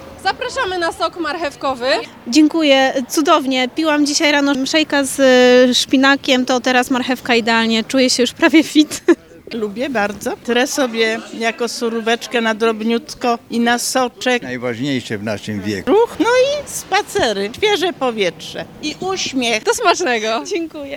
Ekipa Twojego Radia na ulicach Stargardu rozdała zdrowe soki, starte z tej tradycyjnej – pomarańczowej.